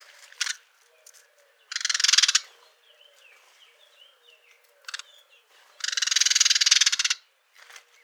PASSER DOMESTICUS ITALIAE - ITALIAN SPARROW - PASSERA D'ITALIA
E 11° 16' - ALTITUDE: +120 m. - VOCALIZATION TYPE: rattle call (threat call against potential intruders).
This call was probably uttered by the female. Background: Blackcap song.